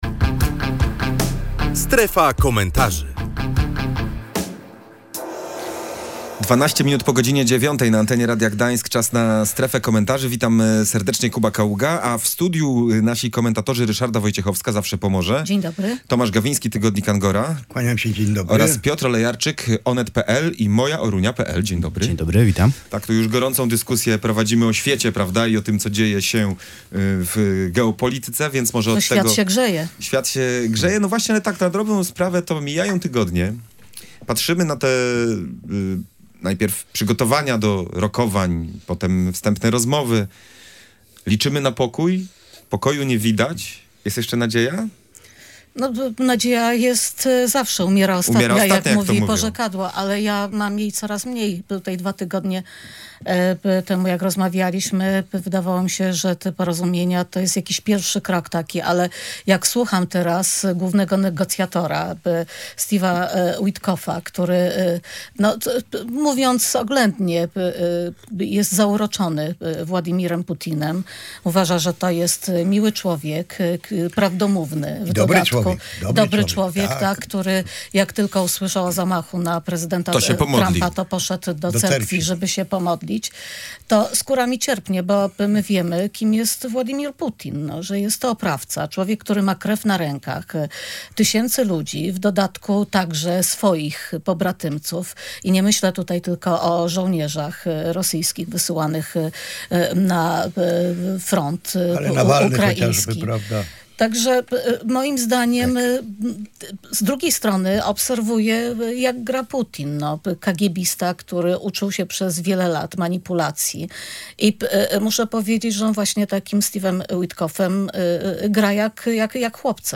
O sytuacji geopolitycznej na świecie rozmawialiśmy w „Strefie Komentarzy”. Mijają tygodnie, patrzymy na przygotowania do rokowań, wstępne rozmowy i liczymy na pokój, jednak go nie widać.